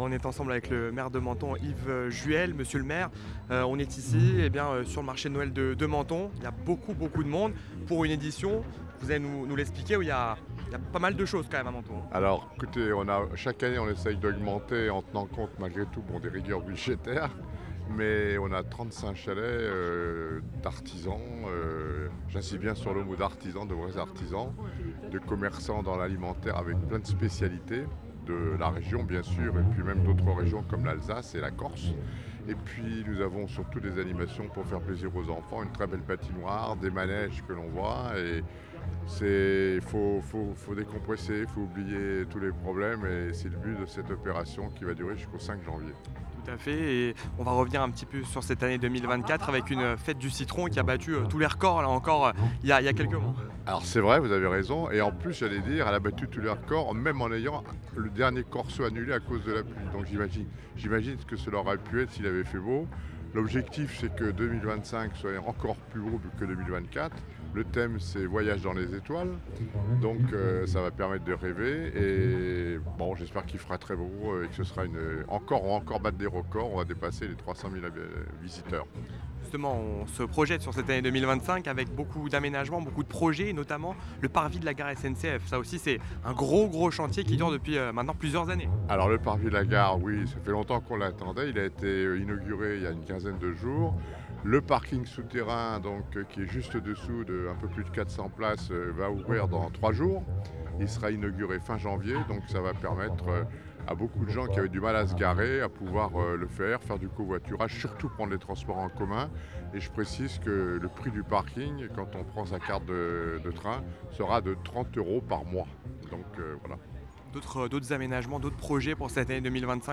Interview des Maires - Episode 5 : Menton avec Yves Juhel